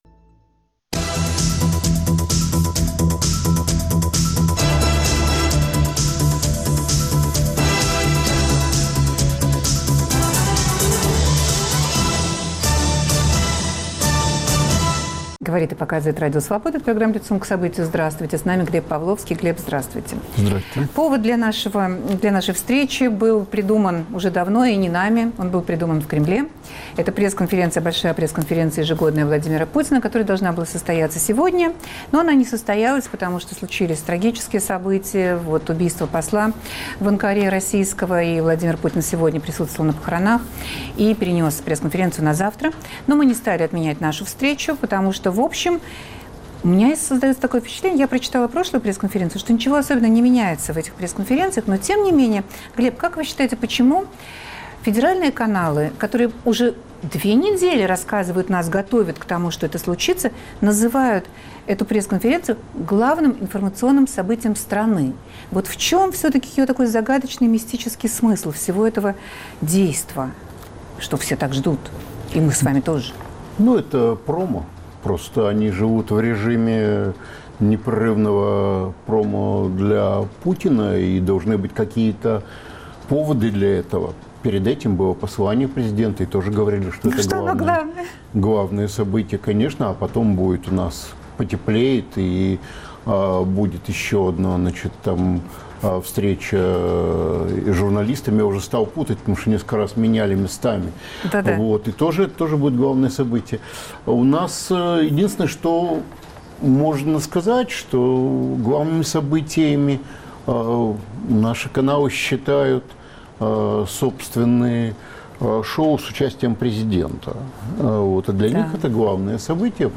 О чем она информирует? Гость студии - Глеб Павловский.